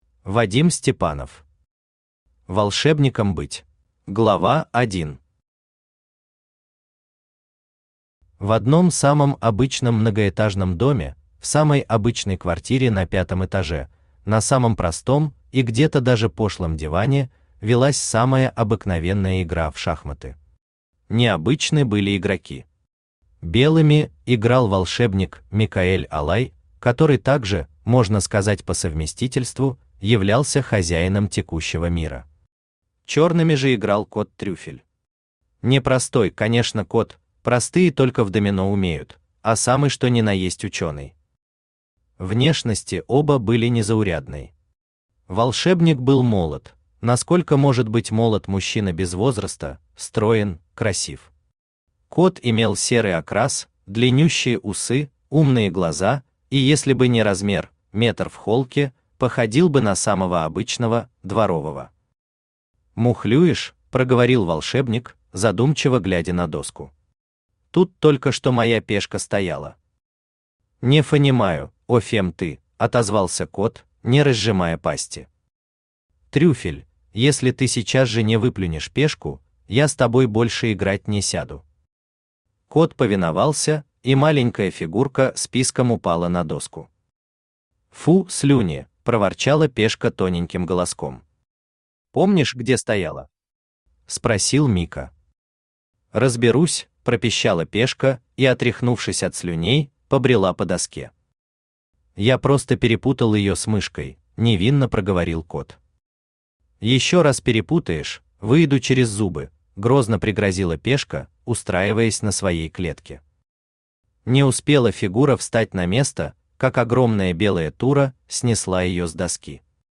Aудиокнига Волшебникам быть Автор Вадим Степанов Читает аудиокнигу Авточтец ЛитРес.